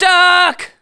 1 channel
SCI-GRENADE2.WAV